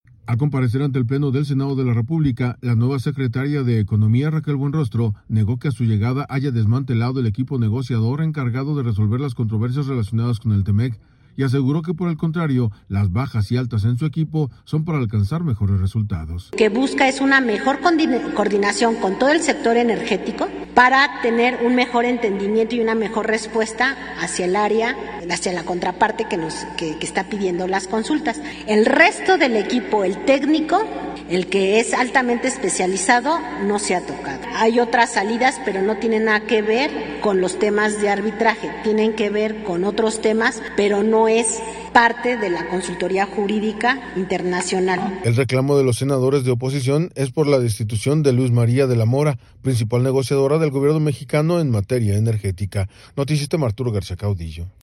Al comparecer ante el Pleno del Senado de la República, la nueva secretaria de Economía, Raquel Buenrostro, negó que a su llegada haya desmantelado el equipo negociador encargado de resolver las controversias relacionadas con el TMEC, y aseguró que por el contrario, las bajas y altas en su equipo son para alcanzar mejores resultados.